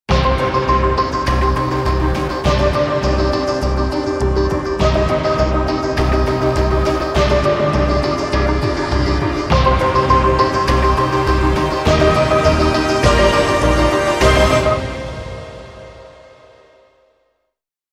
infos - riffs - televise - news - journal